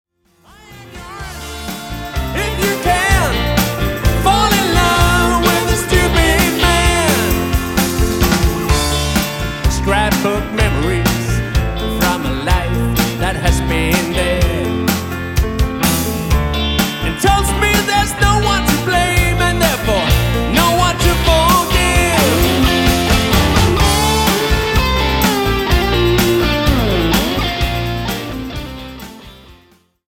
live musik til din fest - pop & rock ørehængere fra 7 årtier
• Coverband